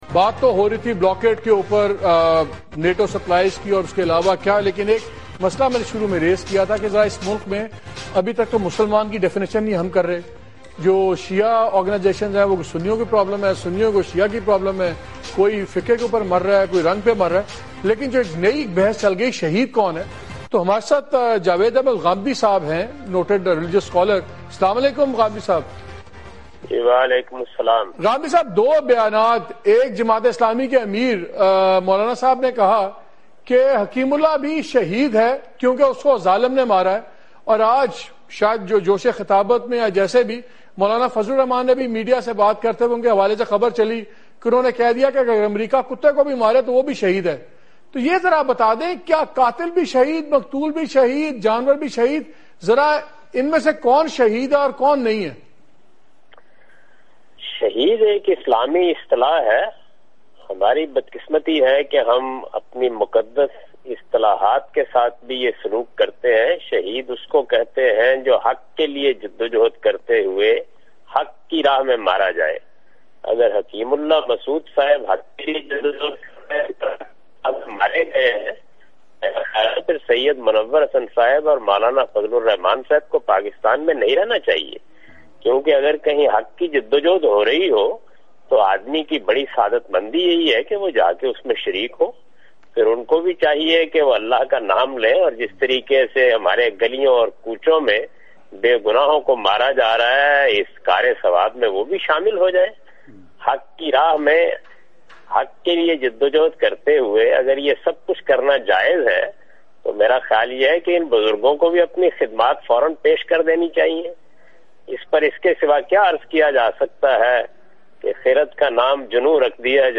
Category: TV Programs / Dunya News /
talk Show